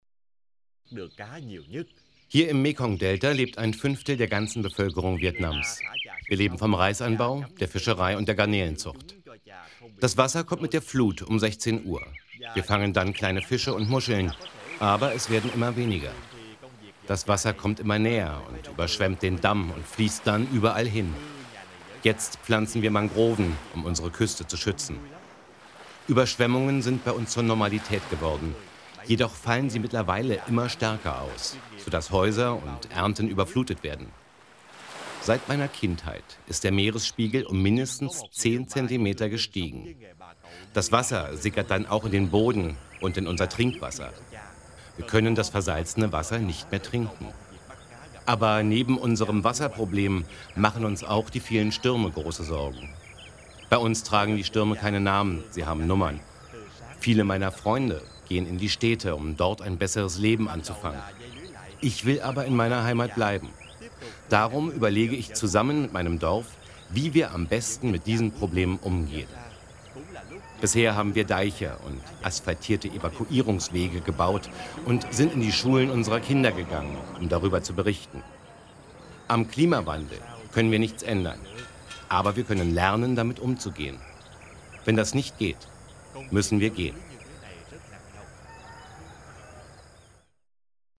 Ein Fischer erzählt: